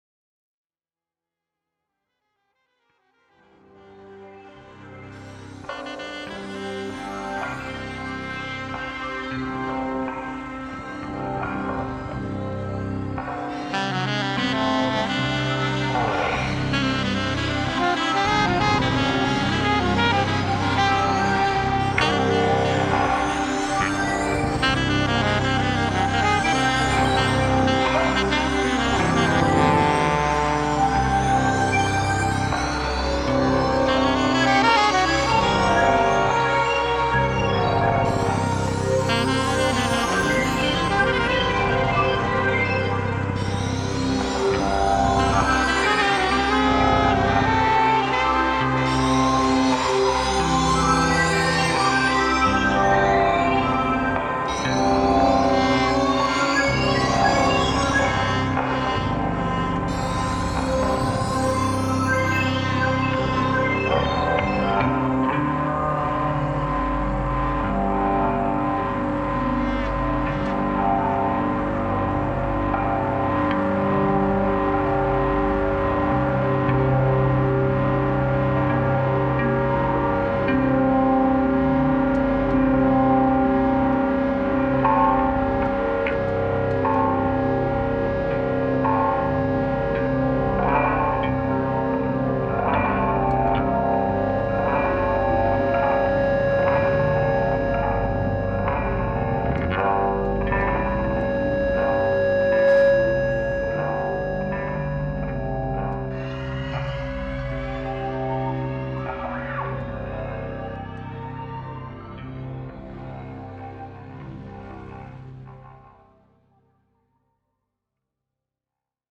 sax and flute
drums and keyboards